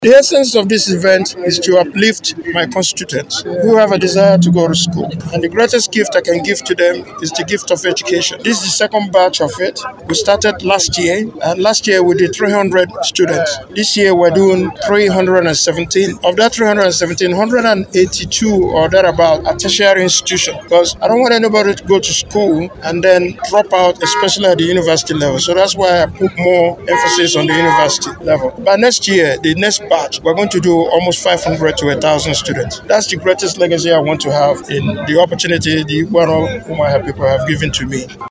Speaking to newsmen, the Ikwuano/Umuahia Federal Constituency Rep Member stated that the beneficiaries numbering 317 of Tertiary, Secondary and Primary students will greatly benefit as the initiative is aimed at easing the burden of school-related expenses for indigent students adding that the greatest legacy he could bequeath to his constituents is the legacy of education.